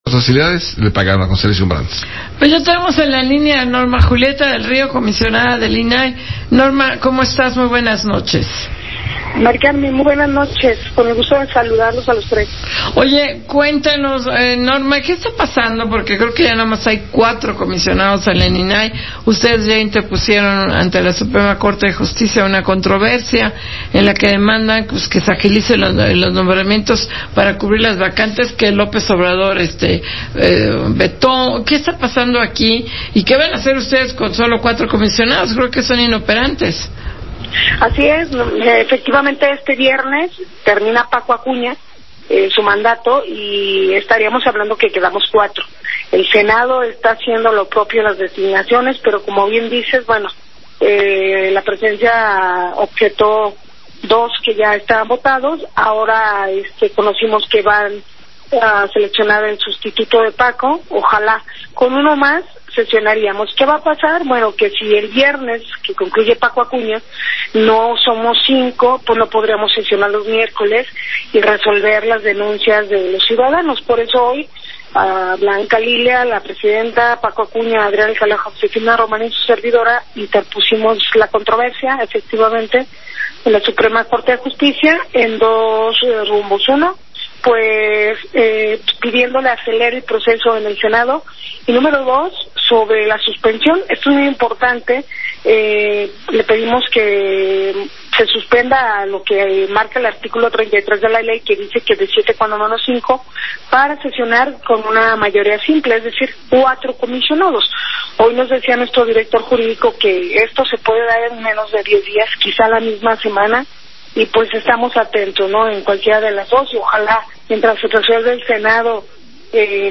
Entrevista con Fórmula Financiera acerca de la controversia constitucional que se ingresó hoy en la SCJN - julietadelrio